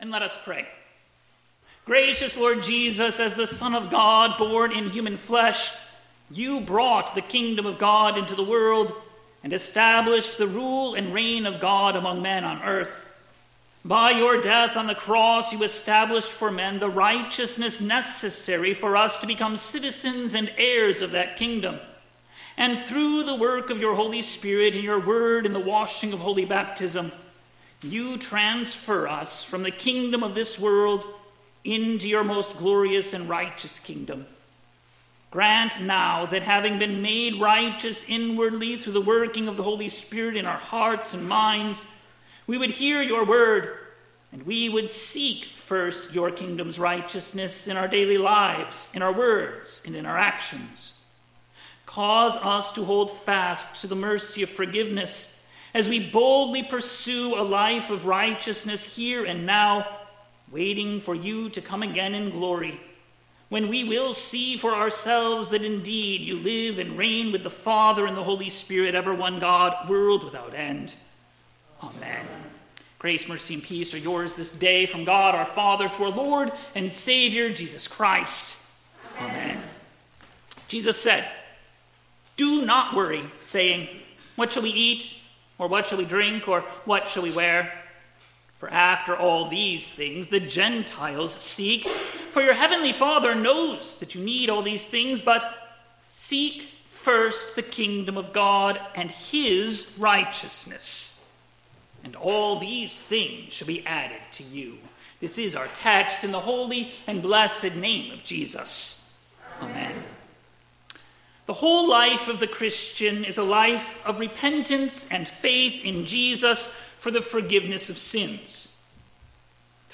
2025-09-21-Sermon.mp3